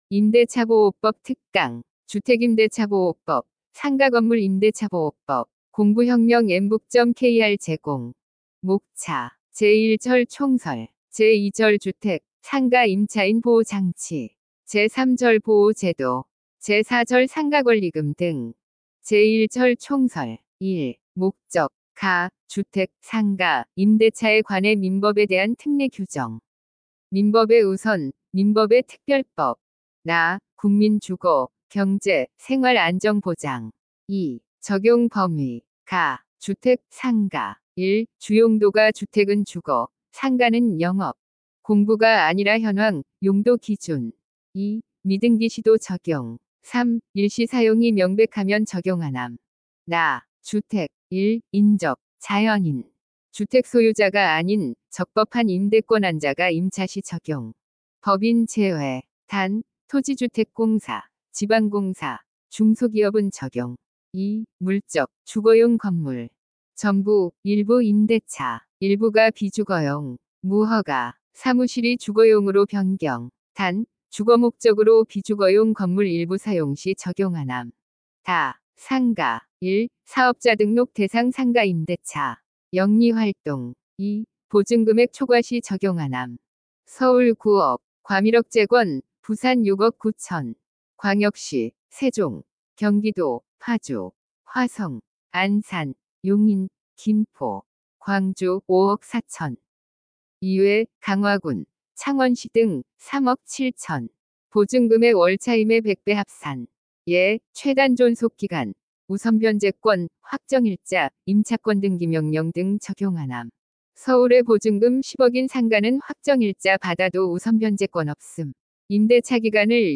강사 : 인공지능(AI)
– 인공지능 성우 이용 오디오 강의
▶ 강의 샘플(4분)
정리한 내용을 TTS(Text to Speech) 기술을 이용해 인공지능 성우가 또렷한 음성으로 낭독해 귀로 들어도 98~99% 이해가 가능합니다.